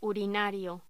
Locución: Urinario
voz